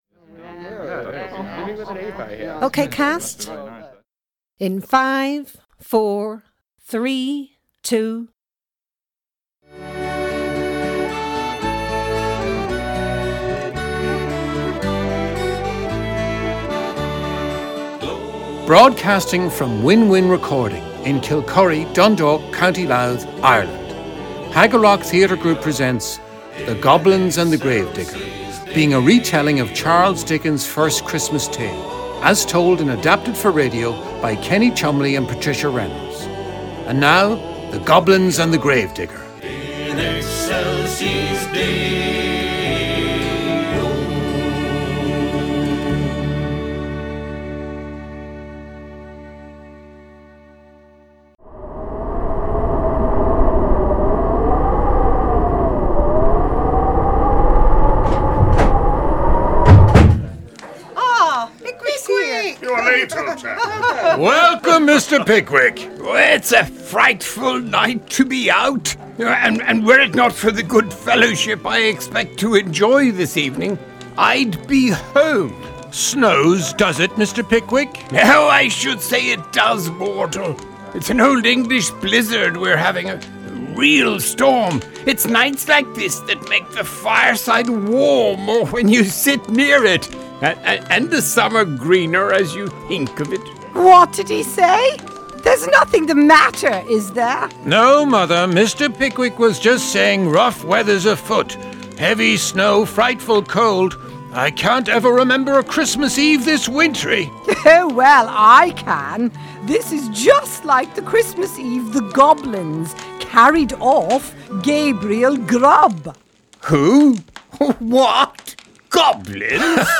Recording Location: Dundalk, Co. Louth, Ireland
Credits: Haggerrock Theatrical Ensemble
Type: Speech/Presentation
192kbps Stereo